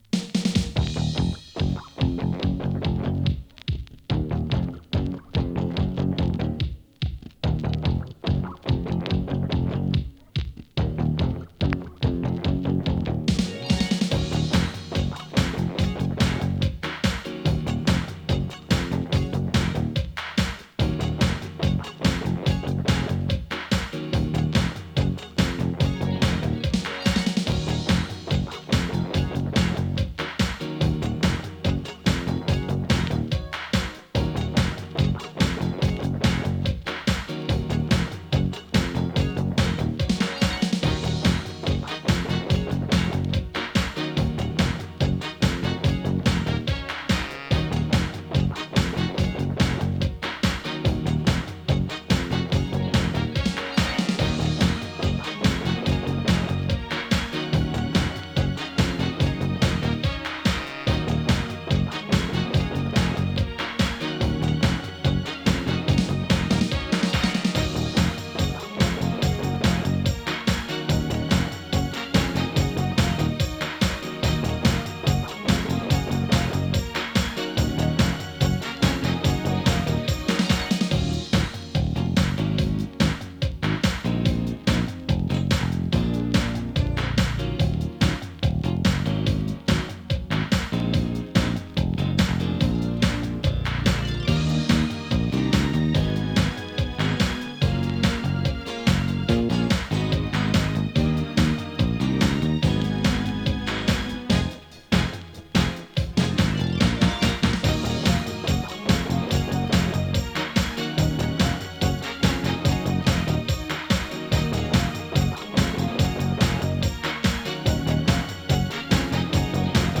彼らのディスコ・ヒットをリメイクした艶やかでザワついたモダン・ダンサー！
[2version 12inch]＊音の薄い部分に軽いチリチリ・ノイズ。
♪Instrumental (7.10)♪